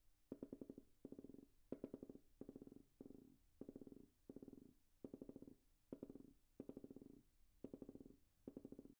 OWI " Purring
描述：由控制弹起塑料球在木桌上创建。
Tag: 动物 OWI 愚蠢